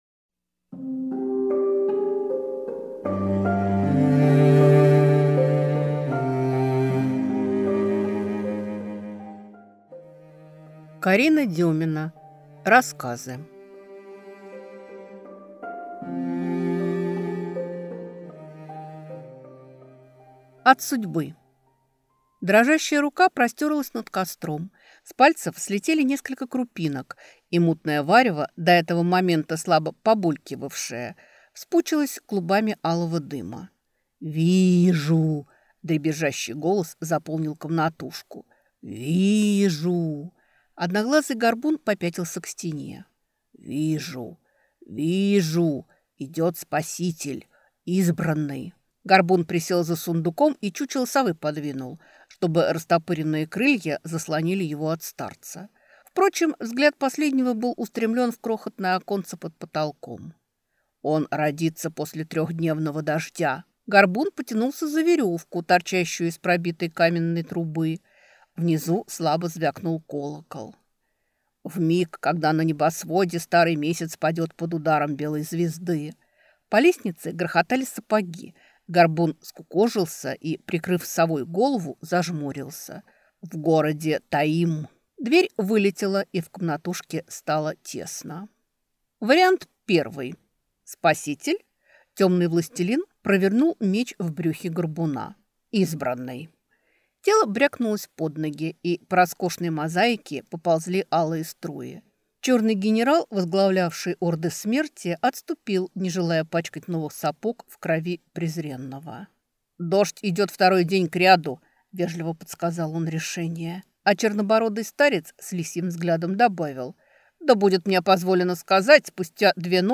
Аудиокнига Сборник рассказов | Библиотека аудиокниг